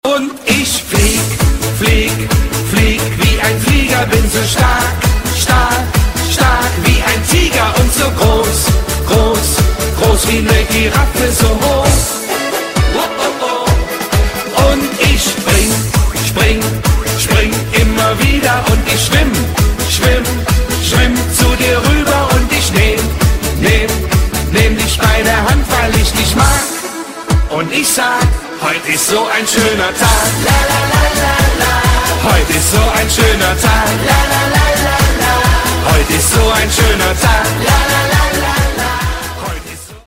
German Drinking Song